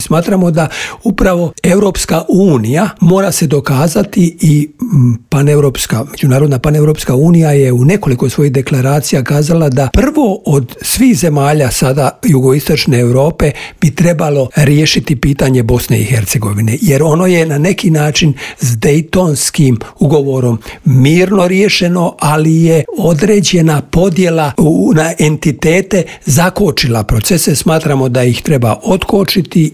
O tome, važnosti mira na Starom kontinentu, ali i proširenju Europske unije razgovarali smo u Intervjuu Media servisa.